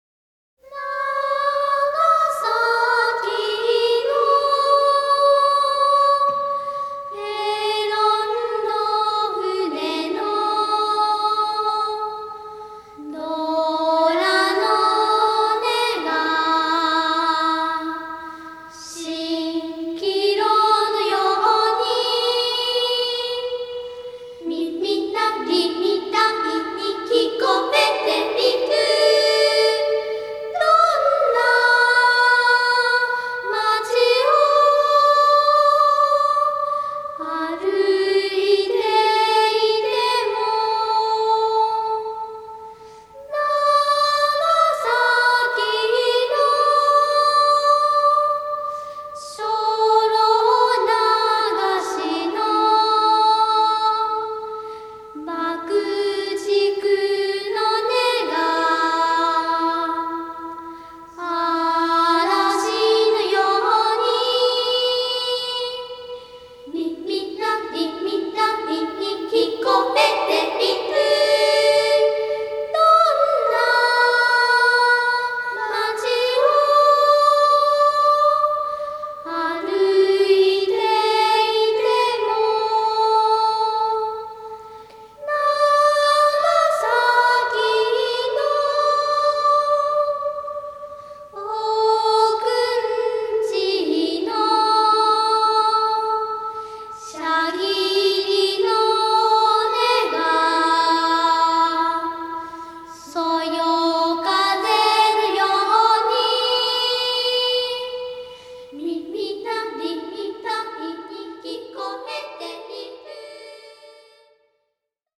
エレキ・チェロの導入。